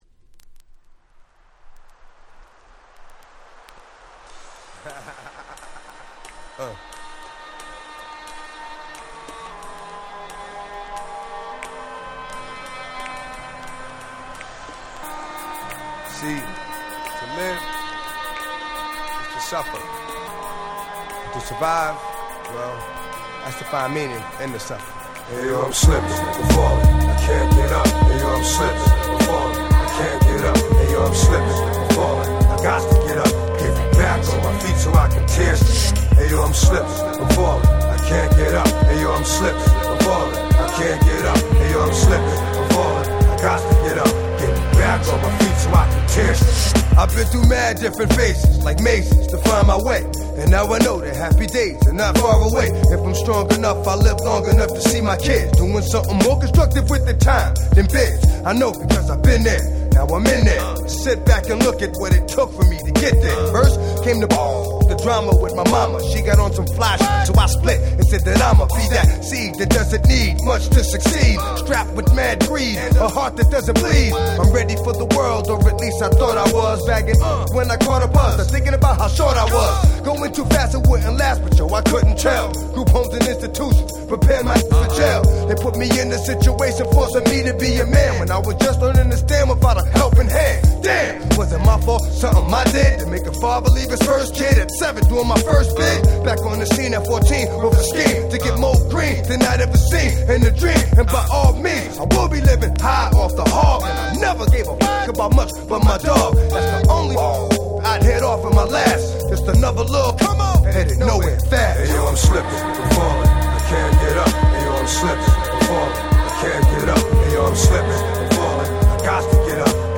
98’ Smash Hit Hip Hop !!